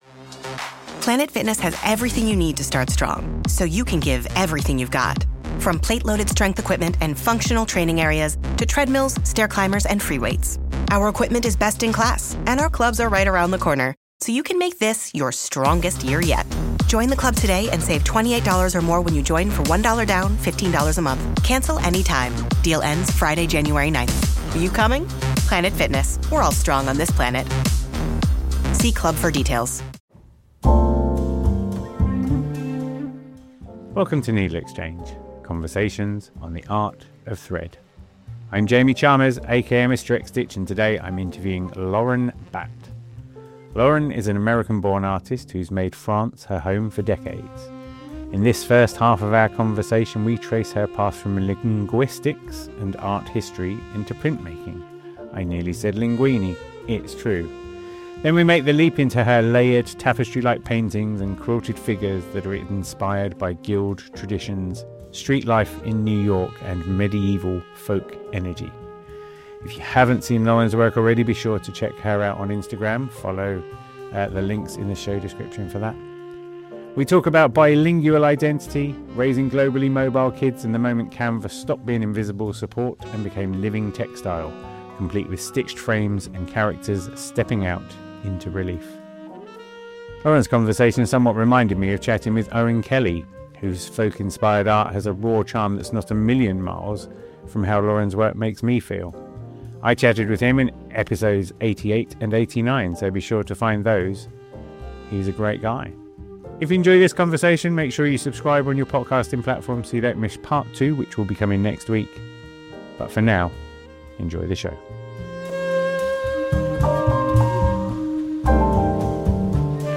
In this episode of NeedleXChange I interview